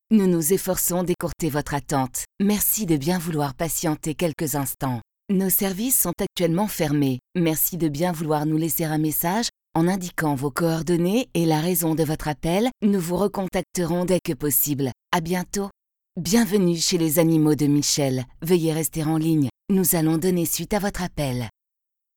Voice over depuis 1988, je peux aussi bien enregistrer des messages sur un ton jeune et dynamique que grave et posé.
Kein Dialekt
Sprechprobe: Industrie (Muttersprache):